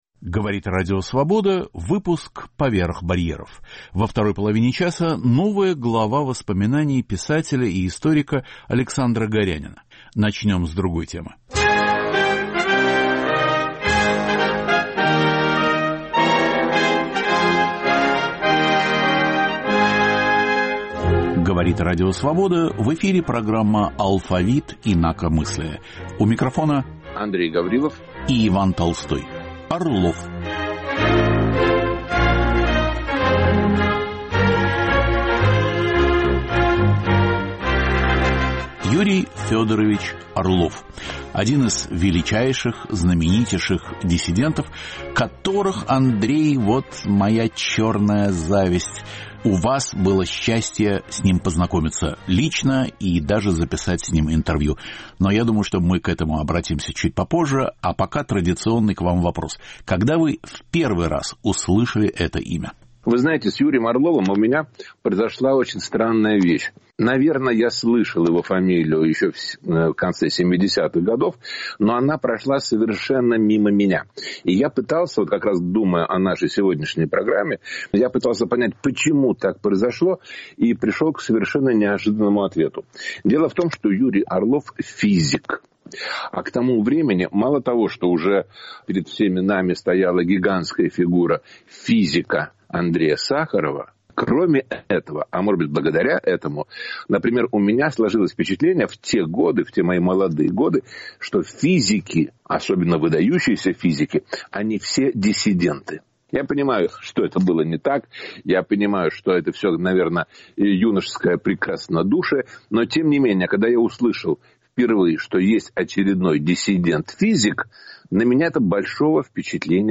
Ведущий Иван Толстой поднимает острые, часто болезненные, вопросы русского культурного процесса: верны ли устоявшиеся стереотипы, справедливы ли оценки, заслуженно ли вознесены и несправедливо ли забыты те или иные деятели культуры?